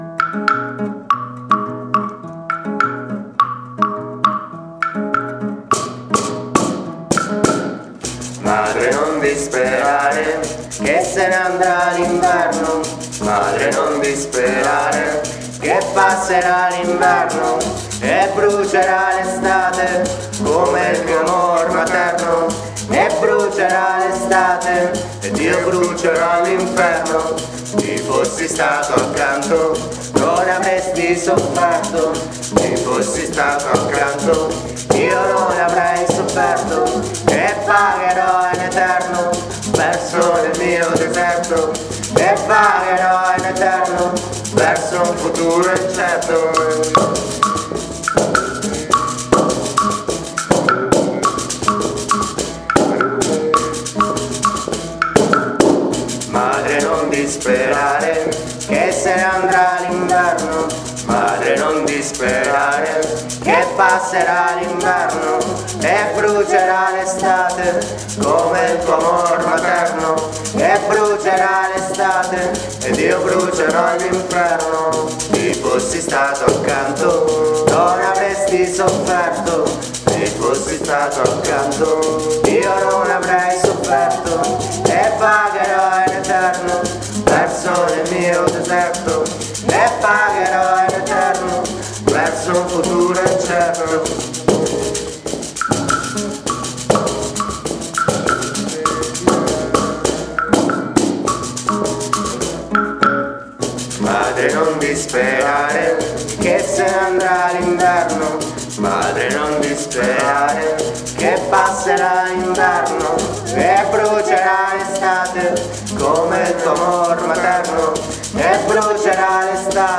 Genere Misto Swing, reggae, cantautorame